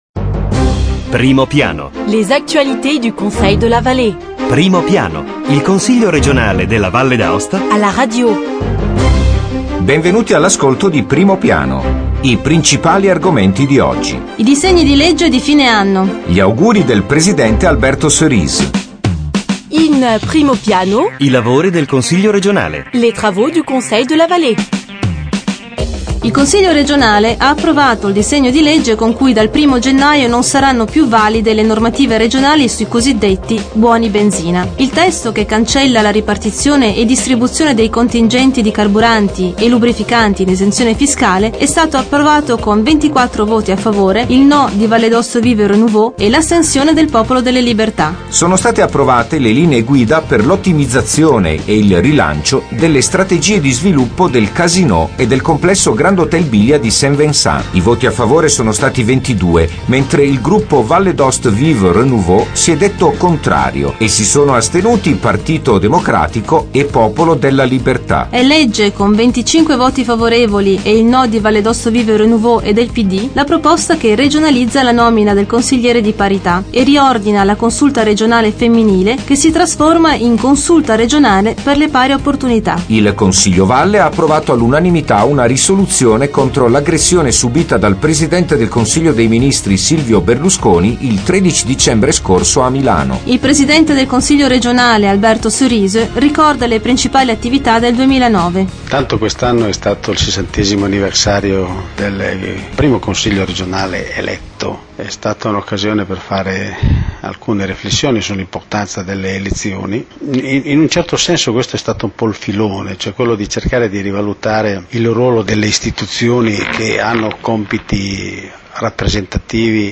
Evénements et anniversaires Documents liés De 22 décembre 2009 à 29 décembre 2009 Premier Plan Le Conseil r�gional � la radio: approfondissement hebdomadaire sur l'activit� politique, institutionnelle et culturelle de l'assembl�e l�gislative. Voici les th�mes de la nouvelle transmission: Les travaux du Conseil de la Vall�e: des projets de loi lors de la derni�re s�ance du 2009 ont �t� approuv�es. Interview au Pr�sident du Conseil r�gional, Alberto Cerise.